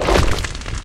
PixelPerfectionCE/assets/minecraft/sounds/mob/magmacube/jump1.ogg at mc116